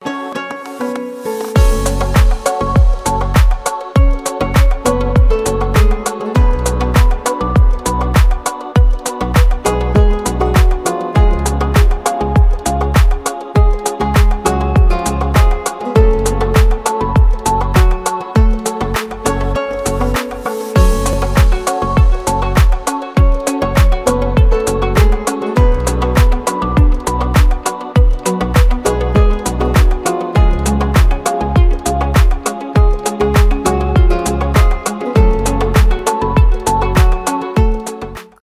танцевальные , без слов , deep house
мелодичные